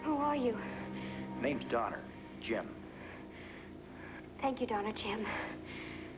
Memorable Dialog